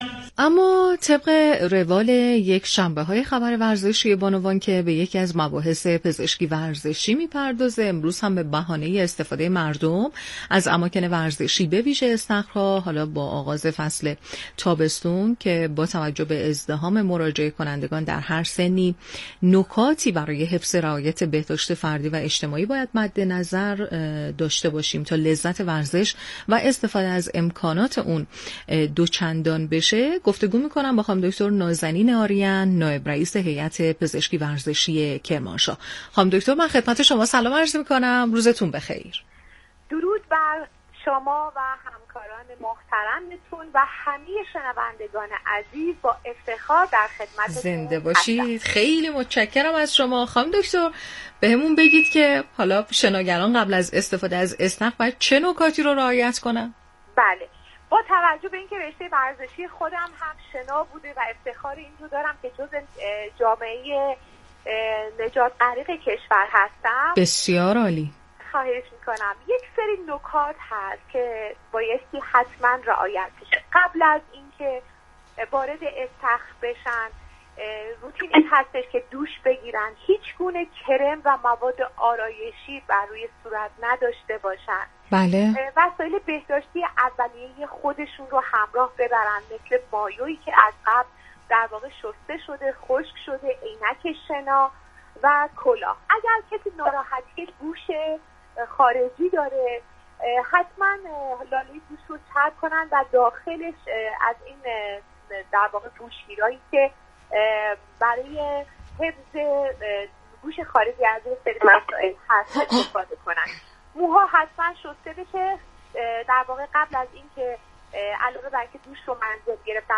در گفت وگو با بخش خبری رادیو ورزش